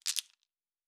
Fantasy Interface Sounds
Objects Small 11.wav